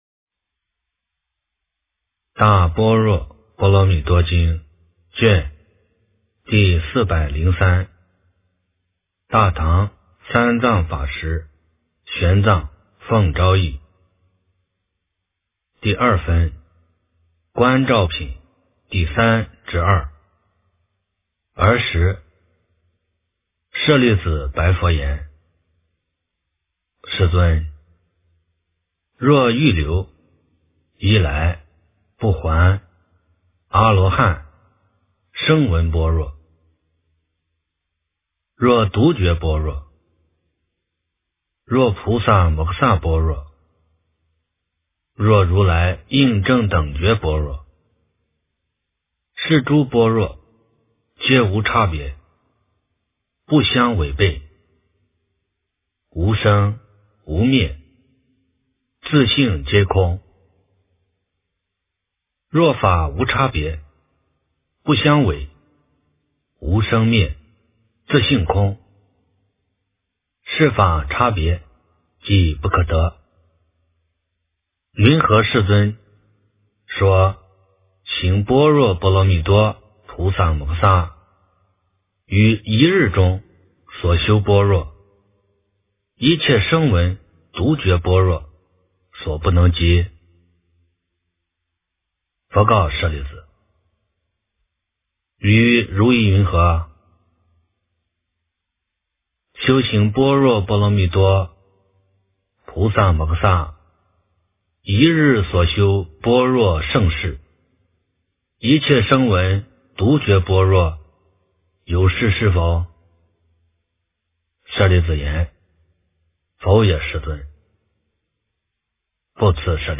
大般若波罗蜜多经403卷 - 诵经 - 云佛论坛